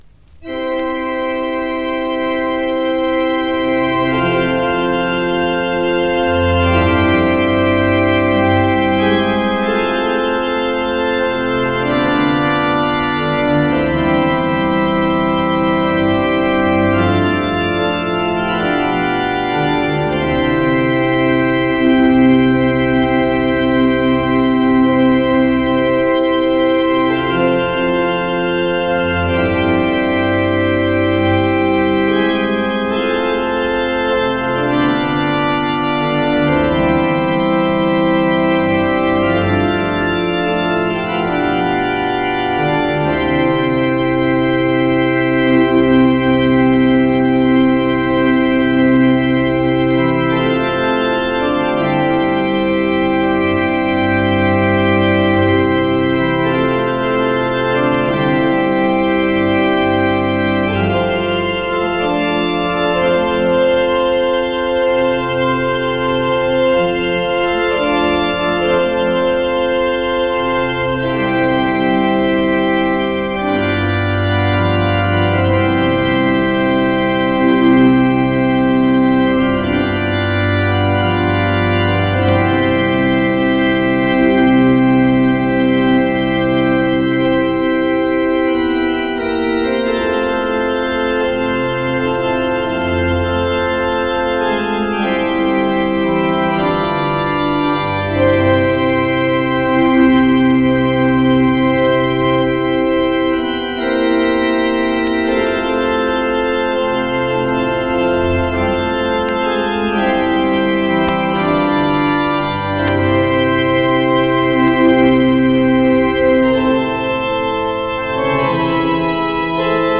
肉声とオルガンがスピリチュアルに響きます。